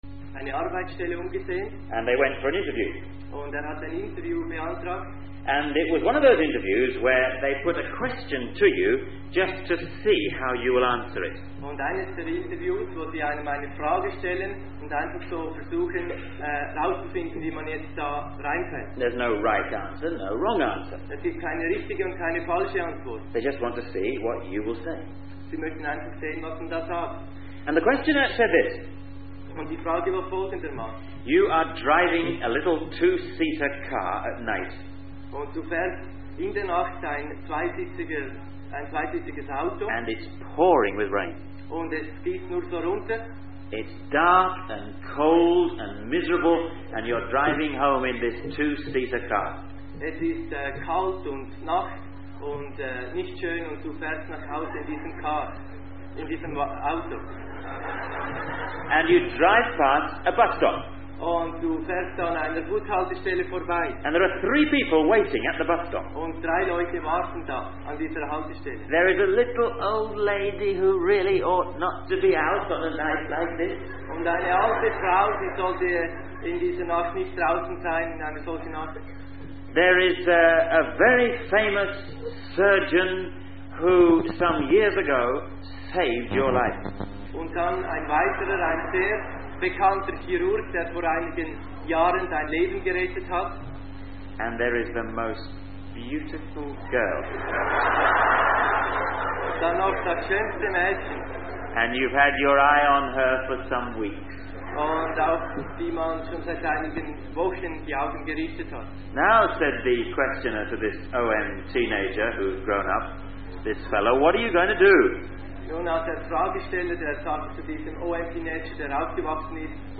The speaker then shares a story about pigeons' ability to find their way back home, illustrating the importance of commitment and belonging. The sermon concludes with a reading from the Gospel of Mark, emphasizing Jesus' sacrifice and the promise of a future in the kingdom of God.